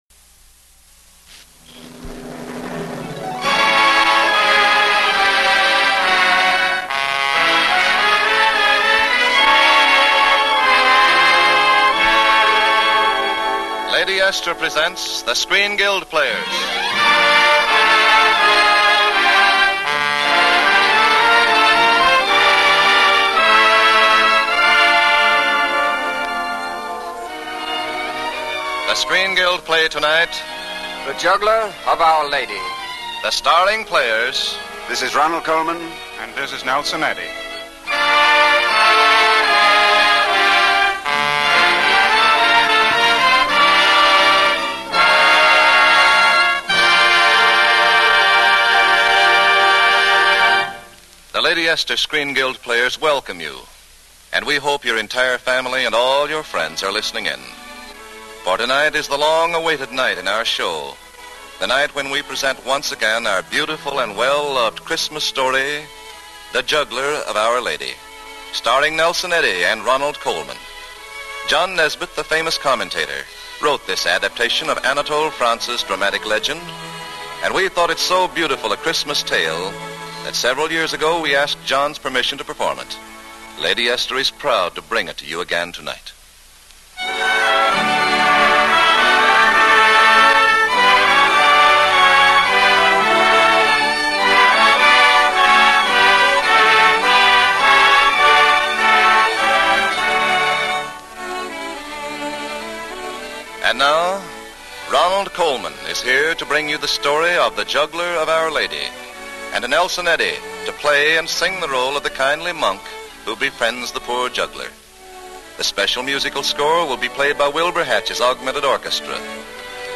For many years during the 1940s, there was an annual Christmas radio broadcast of THE JUGGLER OF OUR LADY, adapted from a story by Anatole France. Ronald Colman narrated this tale in his rich “velvet voice,” while Nelson Eddy sang the role of a monk.